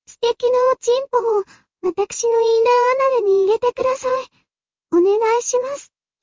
Canary-TTS-0.5Bをつまんでみたで
プロンプトで声を指定できるんでヤンデレとツンデレの声をChatGPTに説明してもろて生成してもろた
CanaryTts_0_Yandere.mp3